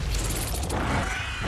Drownviper_roar.mp3